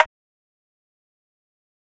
Rim (80 Degrees).wav